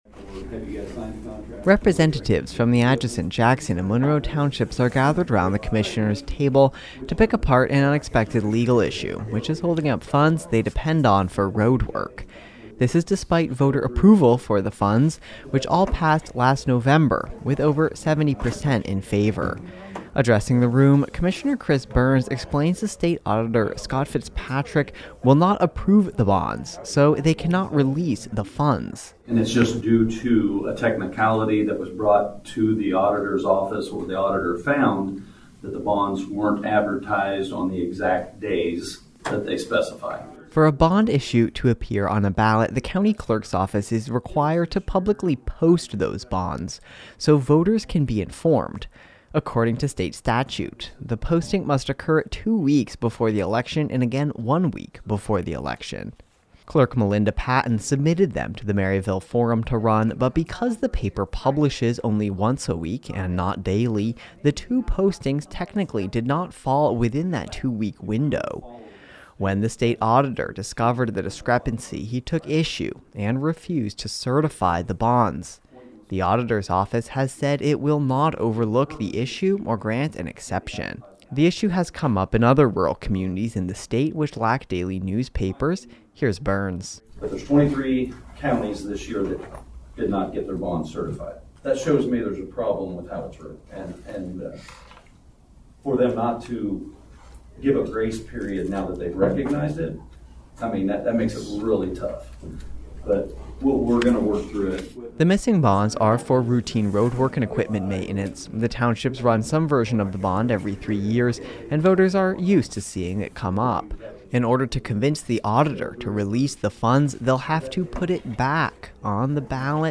News Brief
Representatives from the Atchison, Jackson, and Monroe Townships are gathered around the commissioner's table to pick apart an unexpected legal issue, which is holding up funds they depend on for road work.
Addressing the room, Commissioner Chris Burns explains the state auditor Scott Fitzpatrick will not approve the bonds, so they cannot release the funds.